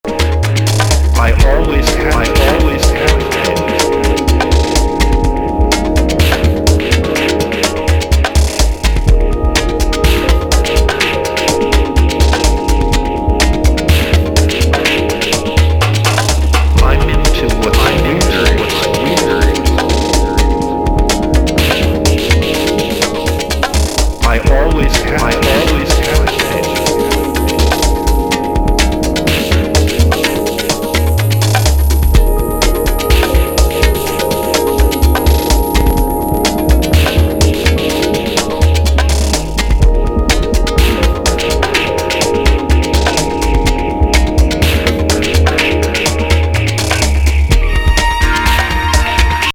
パンの振られた電子音に、心地
良いストリングス。突如現れる中近東風の笛の音がトリップ感を煽ります!